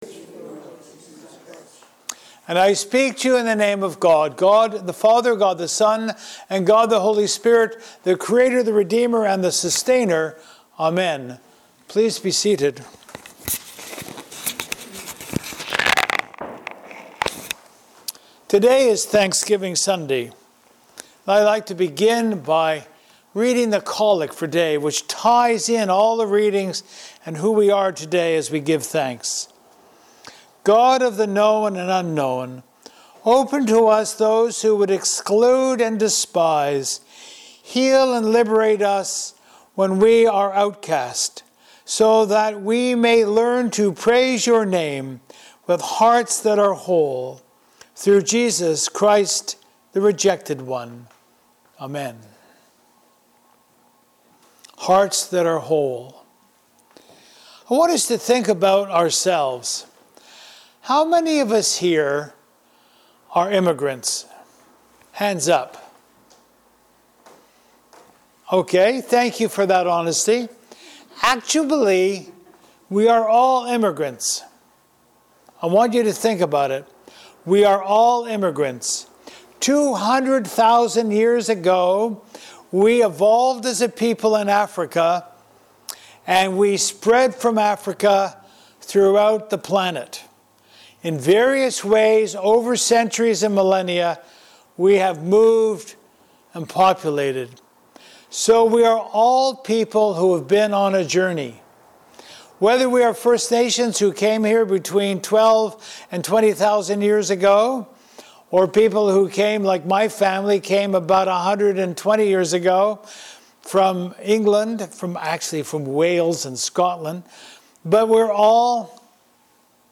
Sermons | All Saints Anglican Church, Agassiz